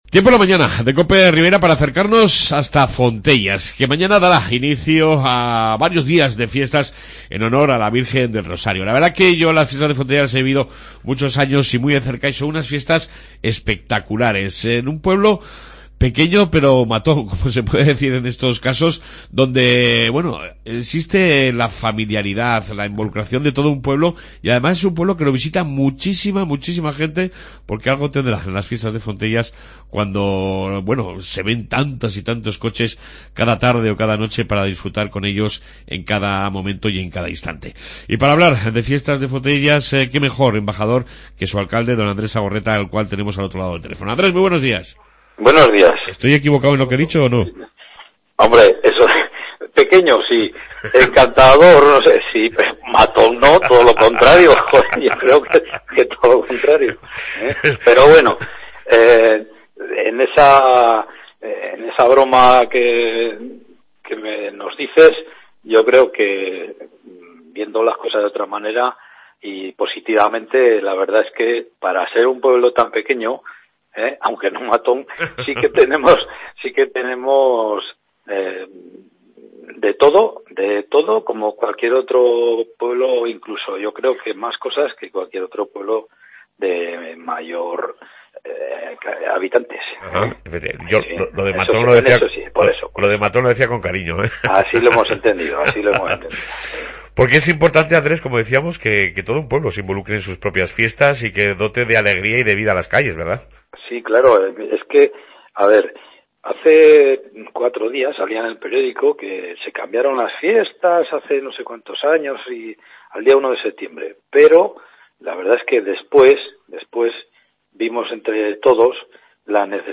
AUDIO: Entrevista sobre Fiestas de Fontellas con su Alcalde Andrés Agorreta.